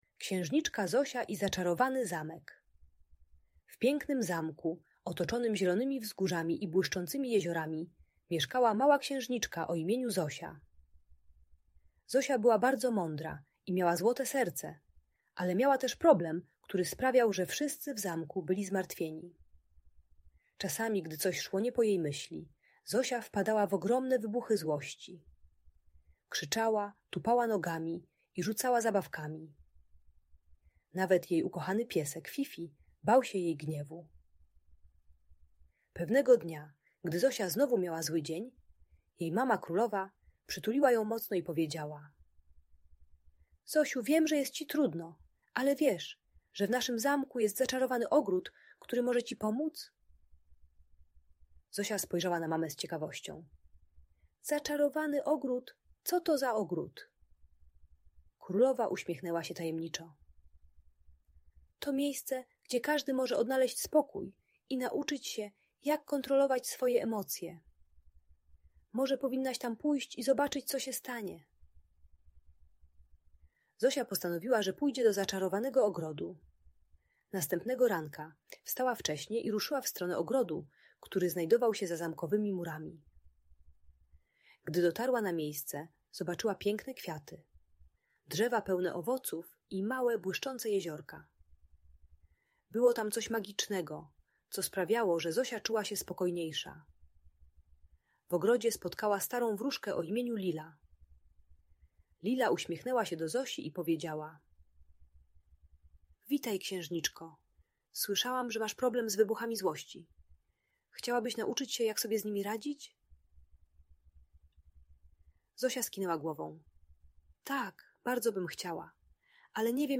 Księżniczka Zosia i Zaczarowany Zamek - Audiobajka